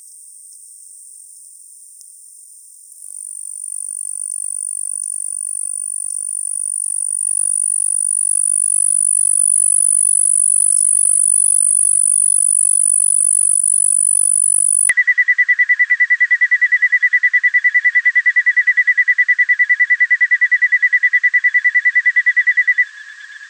Sphagnum ground cricket (Neonemobius palustris) from Ponakpoag Bog, MA.
The call of the Sphagnum ground cricket is a soft, high-pitched trill, which is easy to miss unless your are looking for it.
here to hear the recording of the call at the natural speed, followed by a fragment slowed down 5 times.
I decided to try looking for it at Ponkapoag Bog in the Blue Hills Reservation, about 10 miles S of Boston. Almost immediately after stepping on the wooden planks that run through the soggy Sphagnum bog I started hearing the characteristic, high-pitched tinkling of those small crickets.